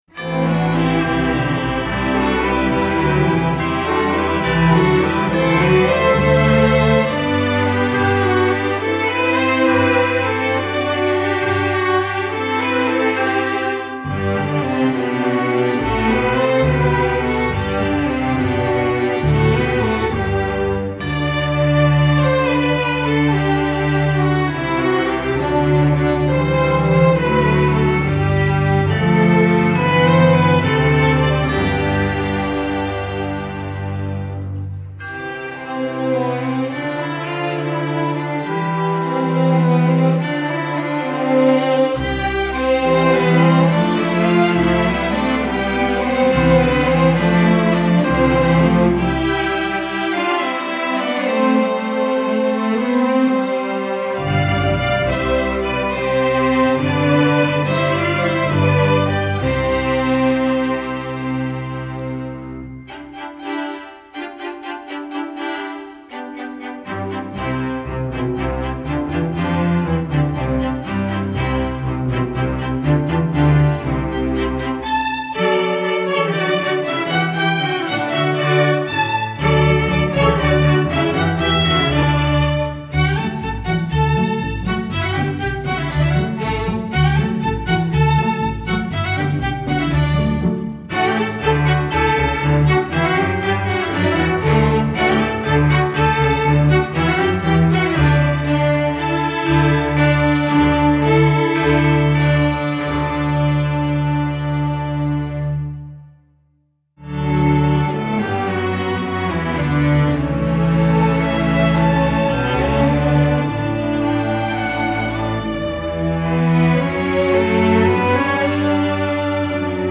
sacred, traditional
Mallets part: